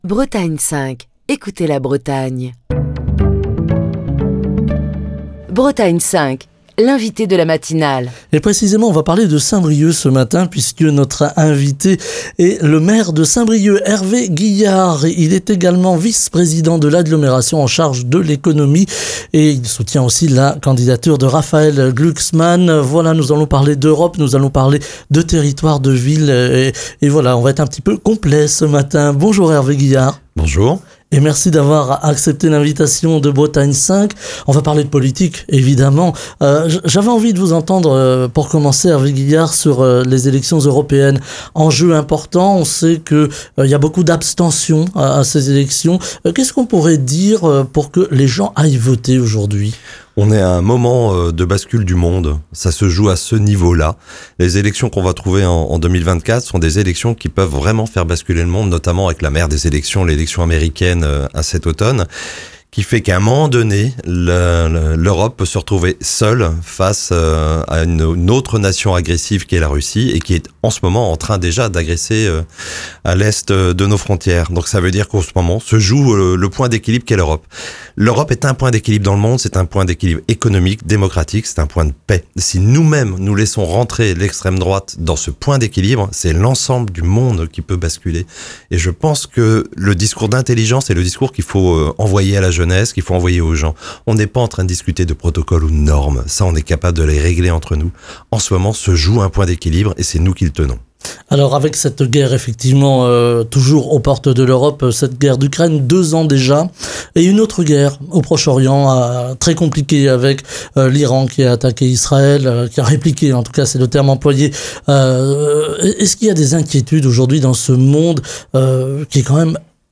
Ce mardi, Hervé Guihard, maire de Saint-Brieuc, vice-président de Saint-Brieuc Armor Agglomération, en charge de l'Économie, est l'invité de Bretagne 5 Matin, pour évoquer la campagne des européennes avec liste "Réveiller l'Europe" portée par Place Publique et le Parti socialiste. Au sommaire, l'Europe sociale, les grands enjeux des flux migratoires, l'économie, et le contexte géopolitique sur fond de guerre en Ukraine et au Proche Orient.